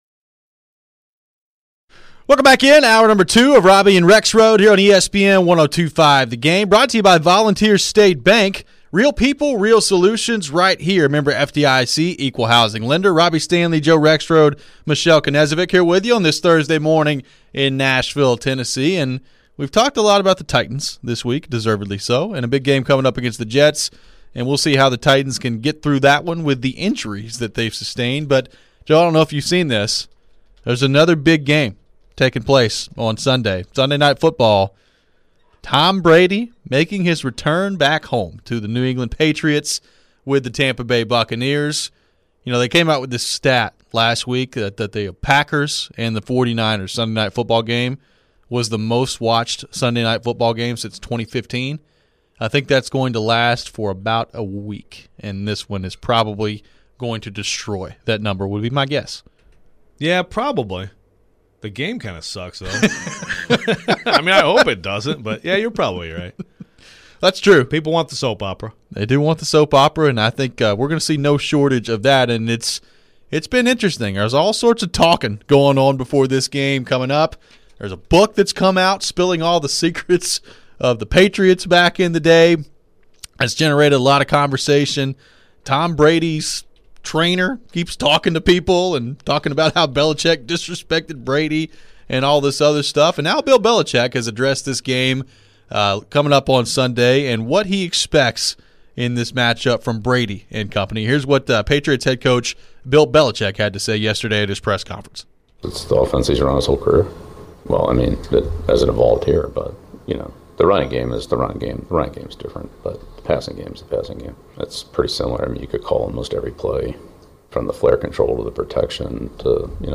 We also hear from both head coaches throwing some shade at each other.